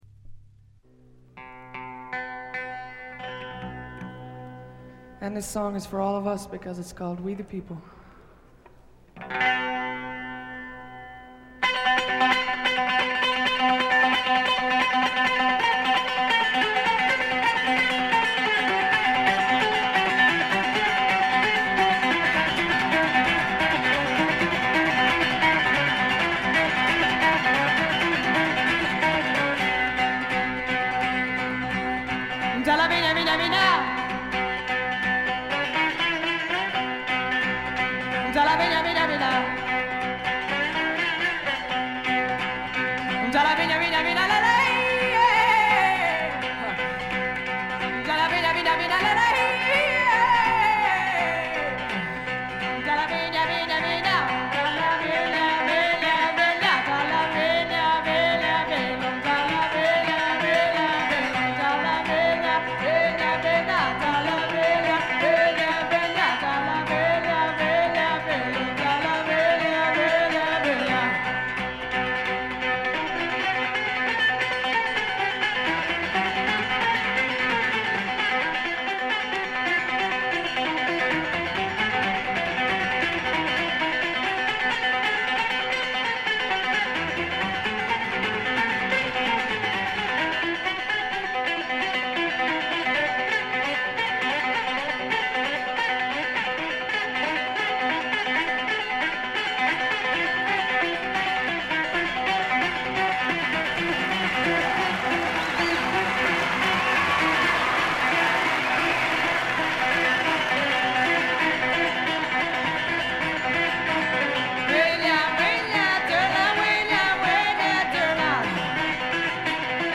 試聴曲は現品からの取り込み音源です。
Recorded At: The Record Plant East, New York City.